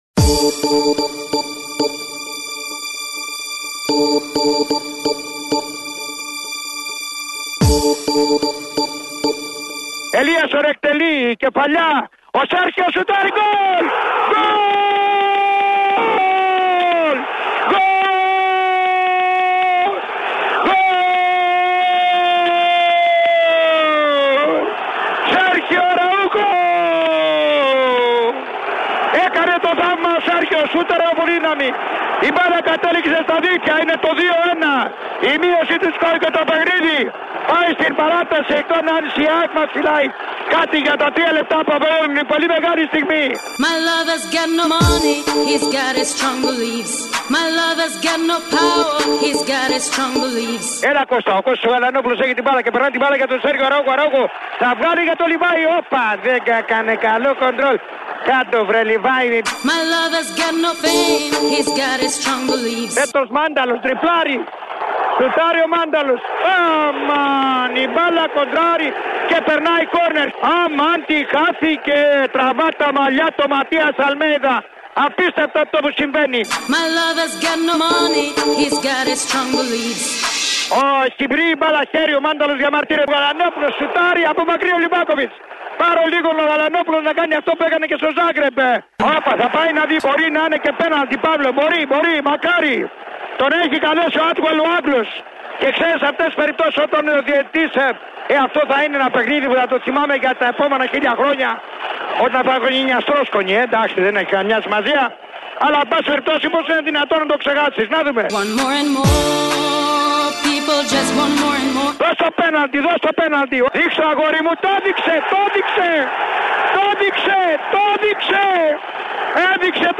Ο κορυφαίος σπορτκάστερ του ελληνικού ραδιοφώνου «τα έδωσε όλα» μεταδίδοντας το απίστευτο comeback των πρωταθλητών και κυπελλούχων Ελλάδας απέναντι στους Κροάτες που είχαν προηγηθεί 2-0 κι ήταν έτοιμοι να πανηγυρίσουν την πρόκριση τους στα play off του Champions League.